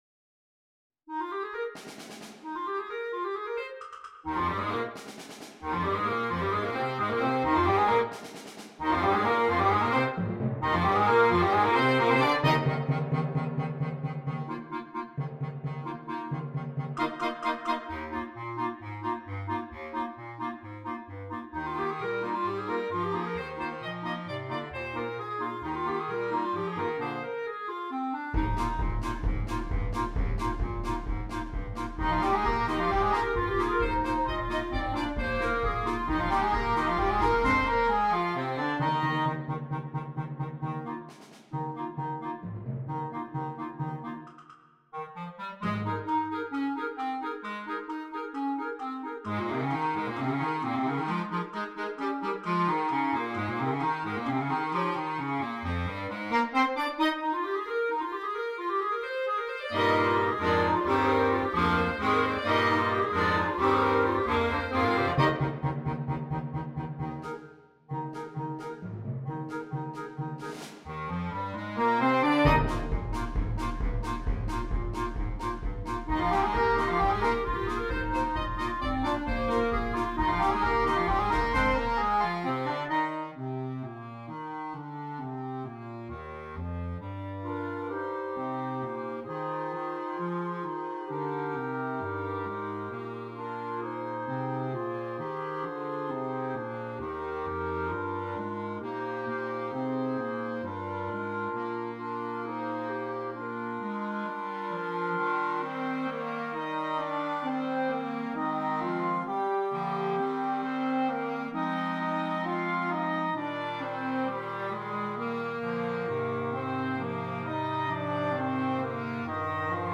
8 Clarinets, 2 Bass Clarinets and Percussion